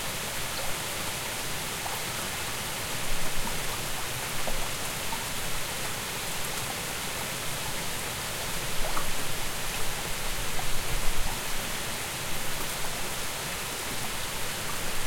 sounds_rain_light.ogg